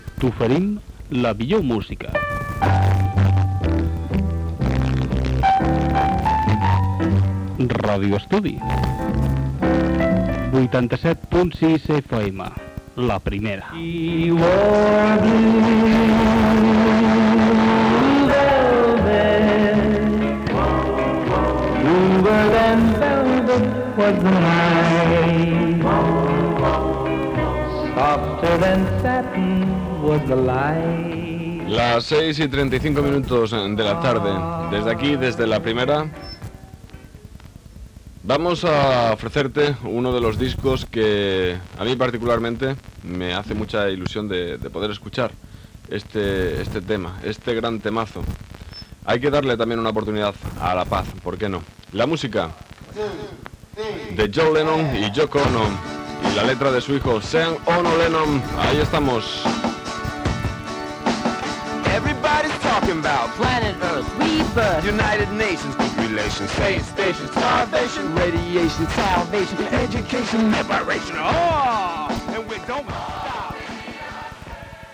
Indicatiu i presentació d'un tema musical.
Musical
FM